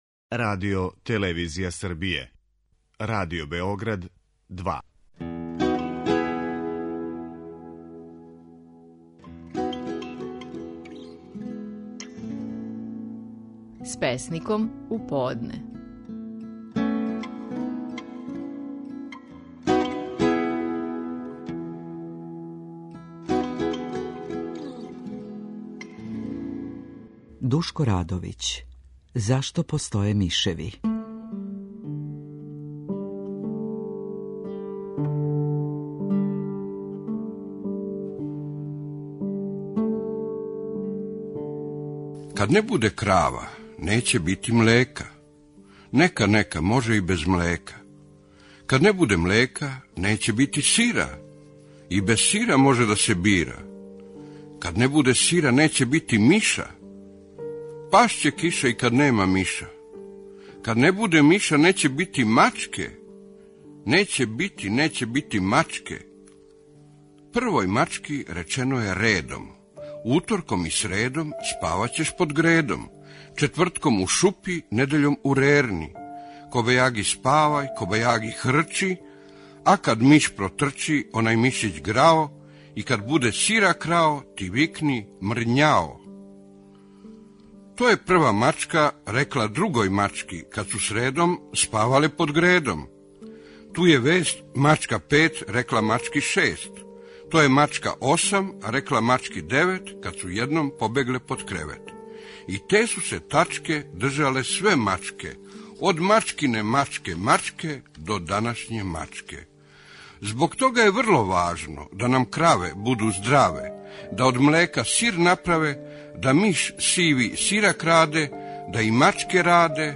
Стихови наших најпознатијих песника, у интерпретацији аутора.
„Зашто постоје мишеви" - назив је песме коју говори Душко Радовић.